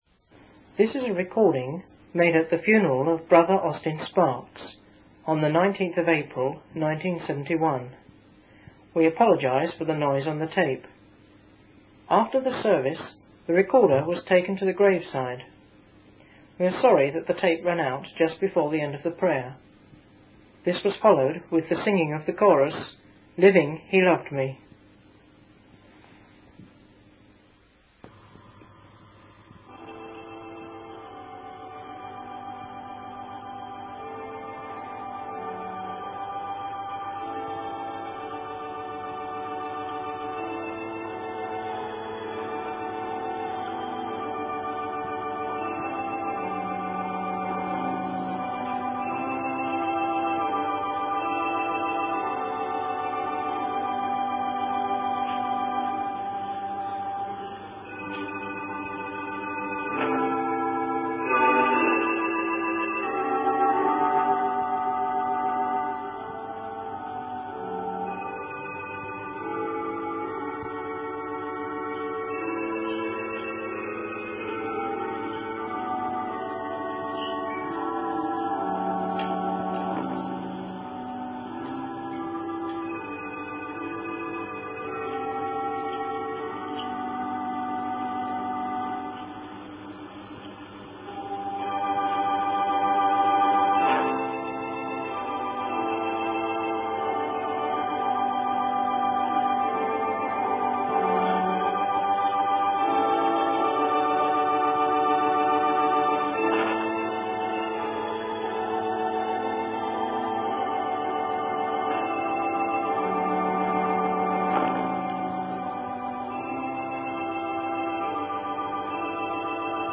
In this sermon, the preacher emphasizes the centrality and supremacy of Jesus Christ. He highlights the power of prayer and the blessing of the coming of the Lord.